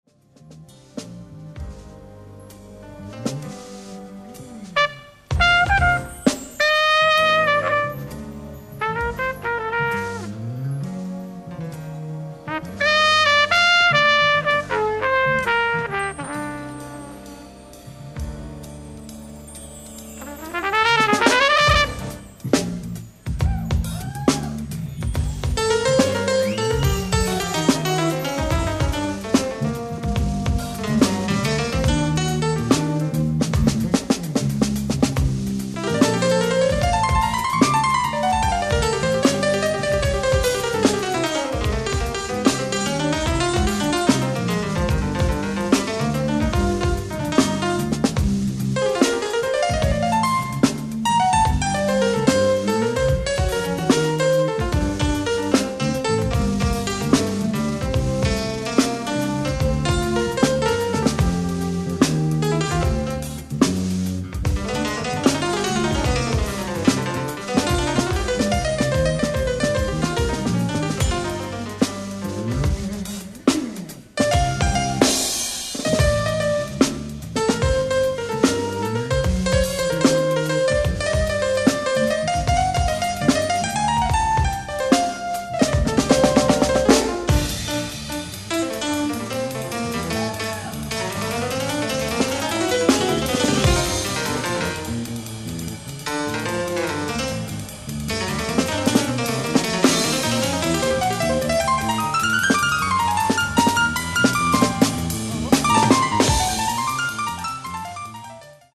ライブ・アット・テラスホール、レバークーゼン、ドイツ